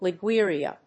Liguria.mp3